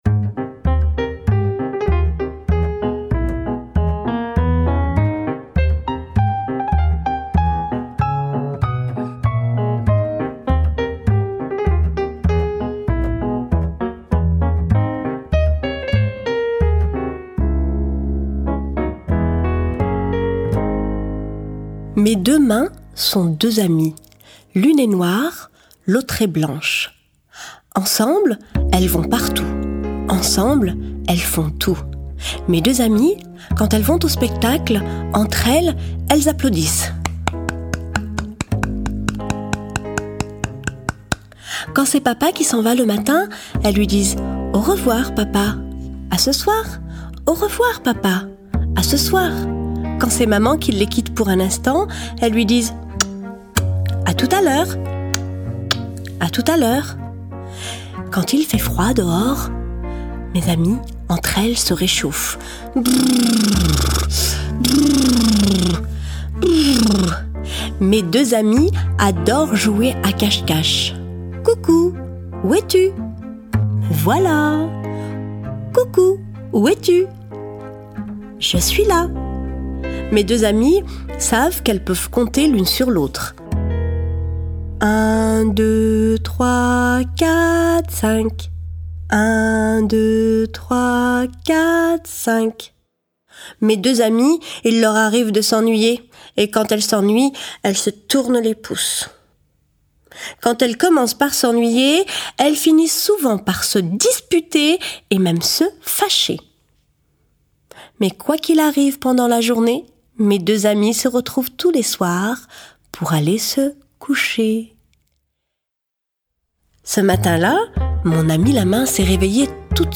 Diffusion distribution ebook et livre audio - Catalogue livres numériques
Une création musicale d’une grande sensibilité, joyeuse et débridée, des randonnées et des contes jouant autant avec les sons que les répétitions. Entre jeux de rimes et de rythmes, l’enfant s’installe comme sur un manège et se laisse porter.